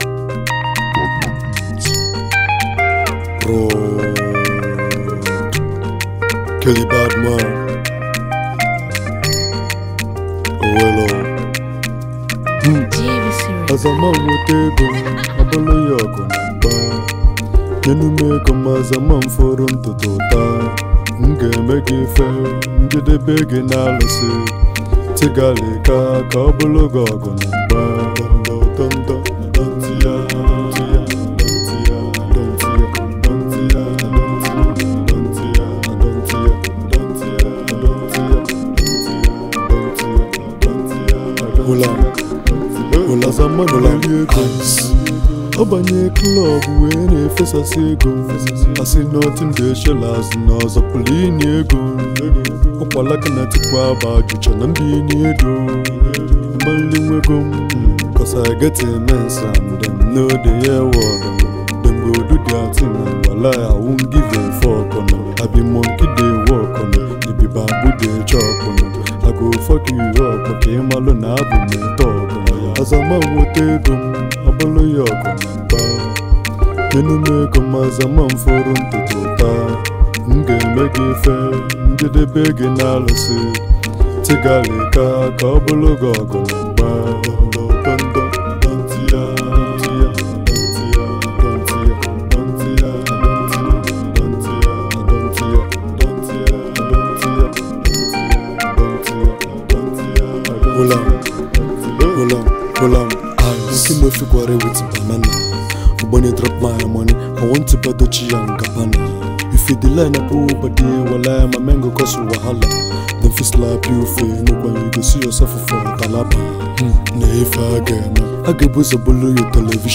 Igbo Rap Sensation
Banging new song